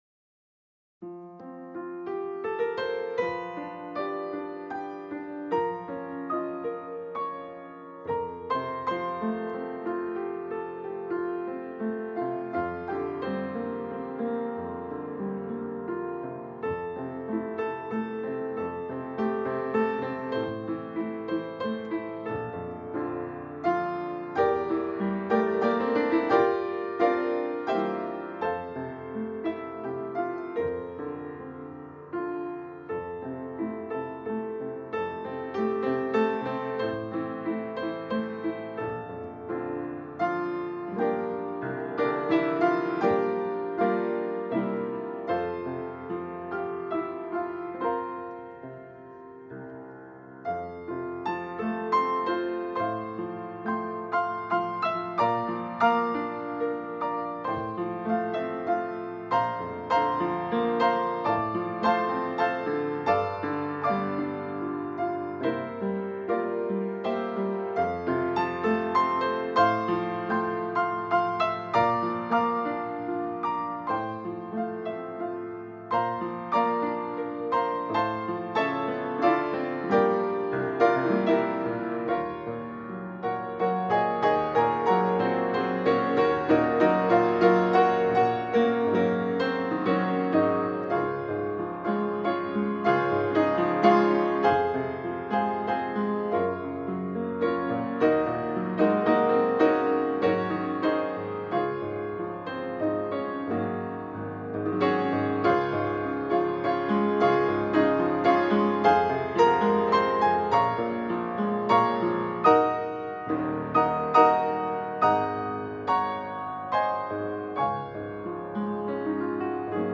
”God Leads Us Along” Piano Solo (See the song’s words at the end of the post)